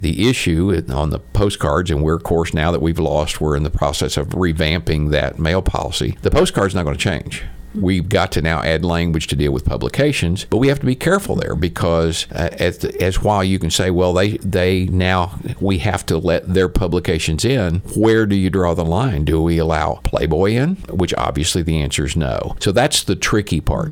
Ahead of the Baxter County Quorum Court meeting tonight, which will seek to appropriate fees associated with the ruling against the jail’s mail policy, Sheriff John Montgomery spoke with KTLO News to discuss the past and the future of the policy.
Sheriff Montgomery discusses how the ruling affects the mail policy moving forward.